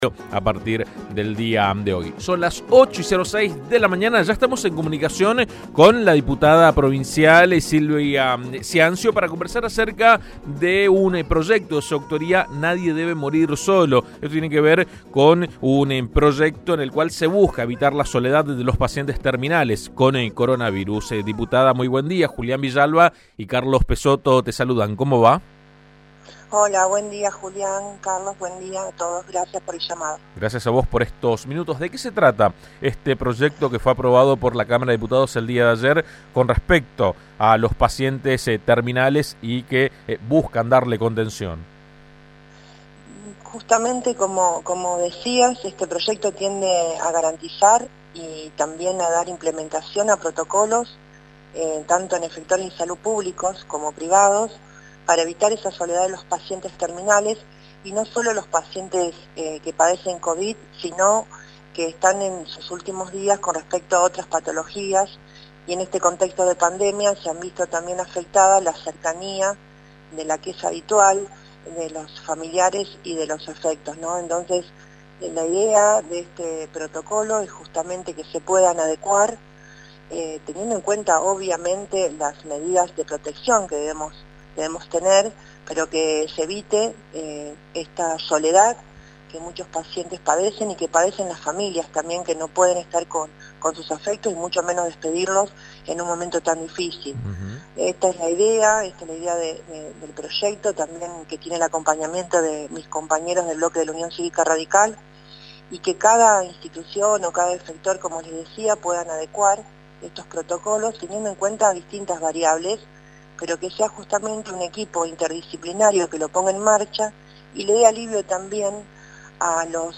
Diputados aprobó un proyecto para que los familiares puedan despedir a pacientes terminales o aislados con covid-19 en Santa Fe. La impulsora del proyecto, Silvia Ciancio, lo explicó en AM 1330